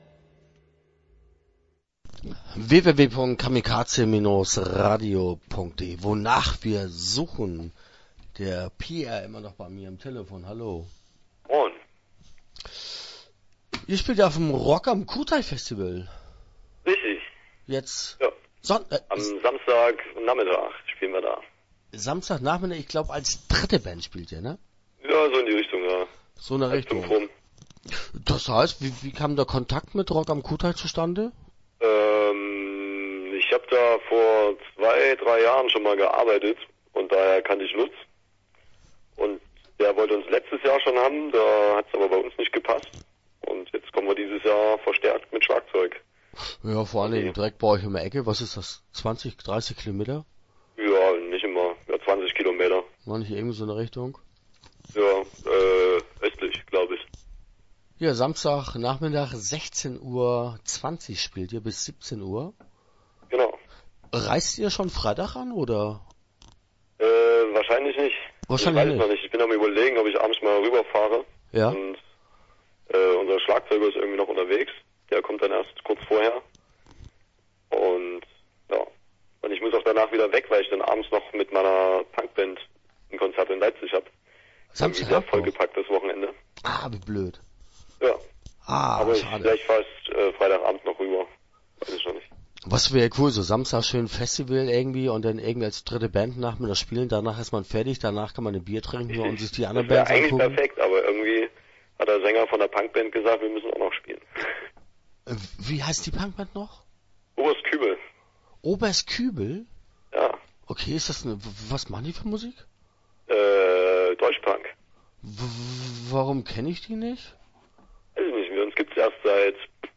Start » Interviews » Wonach wir suchen